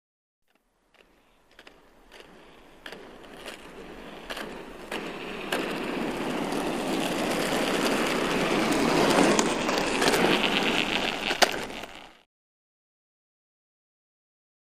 Roller Skating; Skating Towards Mic.